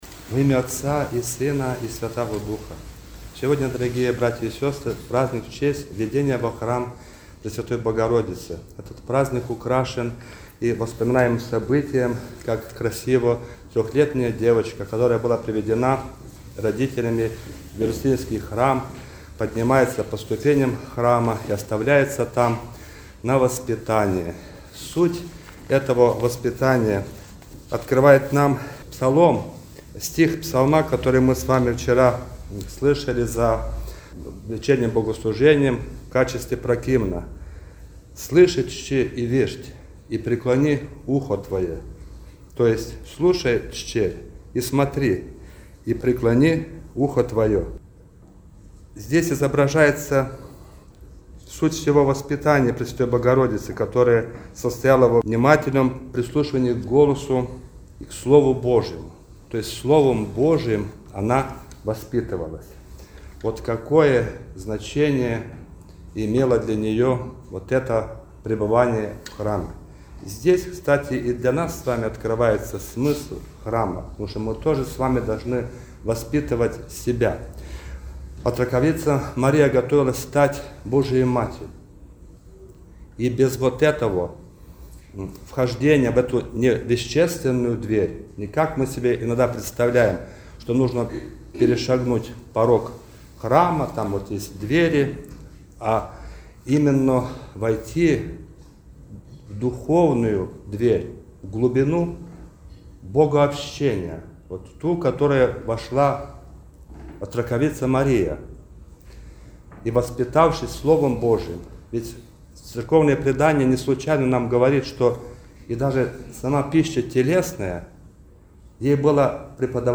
Праздник Введения во храм Пресвятой Богородицы — предвозвестие благоволения Божия к человеческому роду, проповедь спасения, обетование Христова пришествия. Проповедь